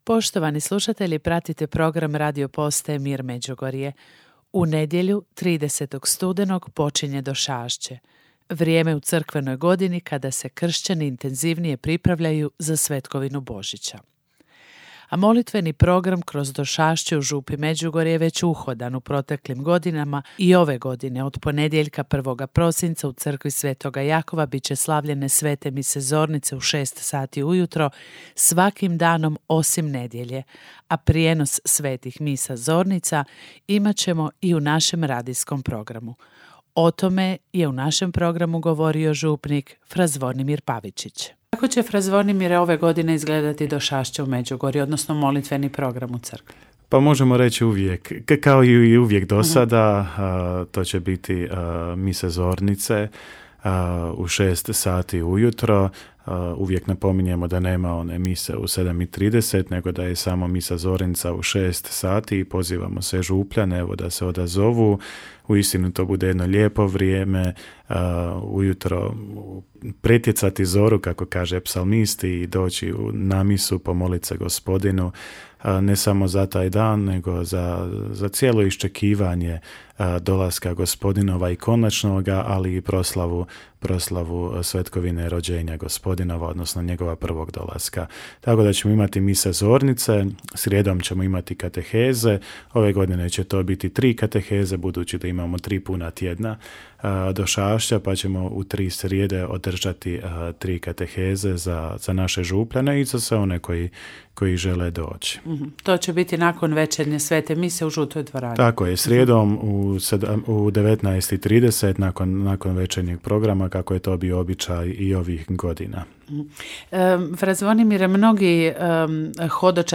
Vijesti